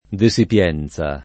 [ de S ip L$ n Z a ]